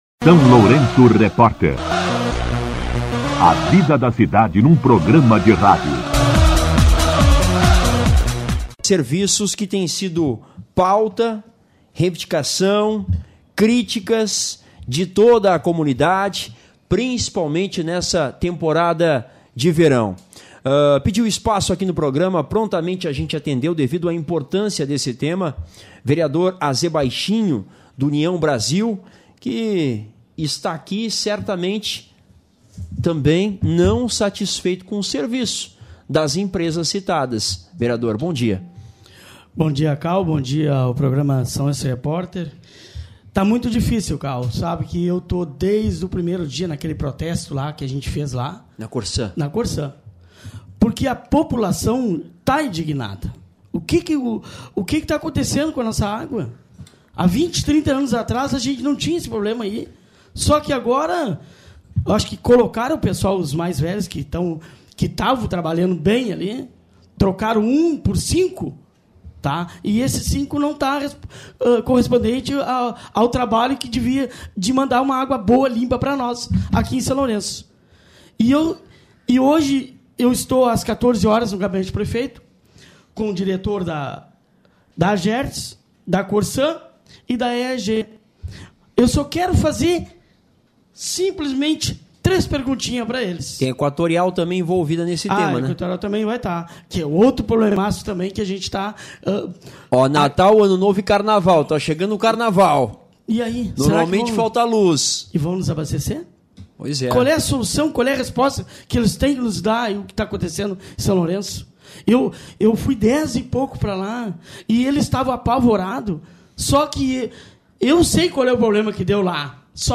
O vereador AZ Baixinho (União Brasil) participou do SLR RÁDIO nesta quarta-feira (21), onde fez duras críticas aos recorrentes problemas no abastecimento de água no município.